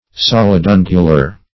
solidungular - definition of solidungular - synonyms, pronunciation, spelling from Free Dictionary
Solidungular \Sol`id*un"gu*lar\, a.